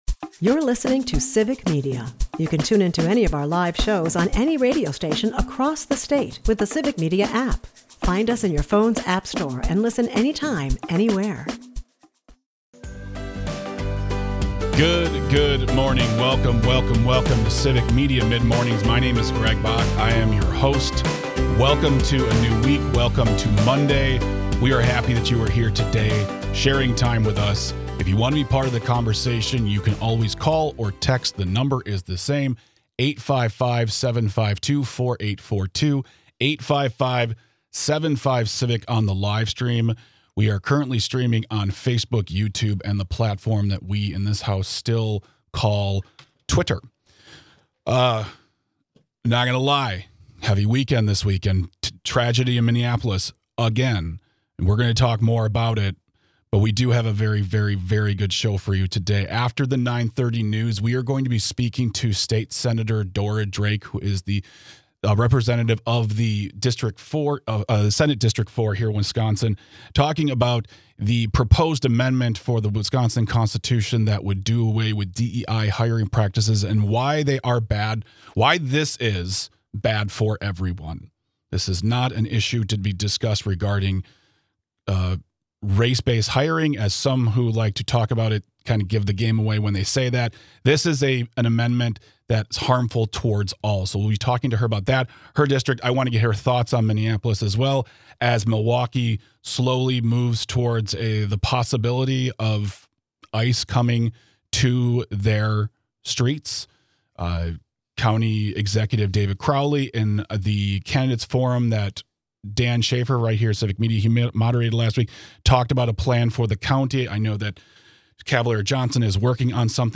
Guests: Dora Drake